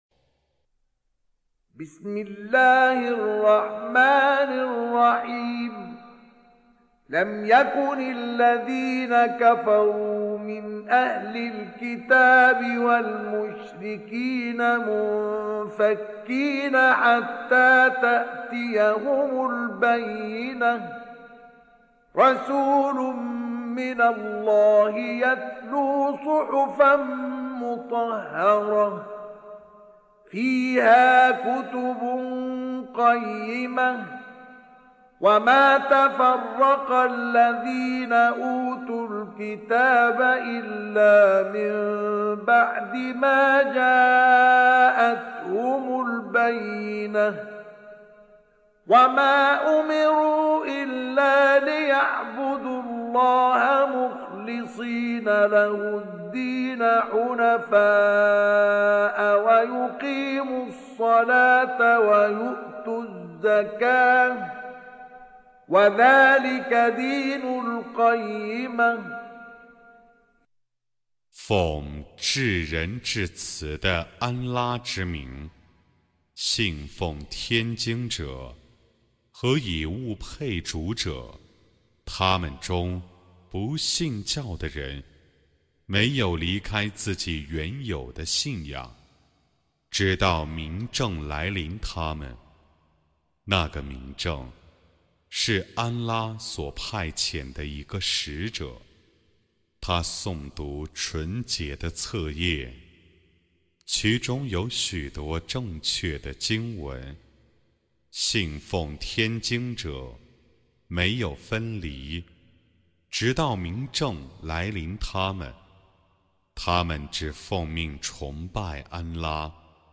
Home Of Chinese Translation With Qari Mustafa Ismail
Surah Repeating تكرار السورة Download Surah حمّل السورة Reciting Mutarjamah Translation Audio for 98.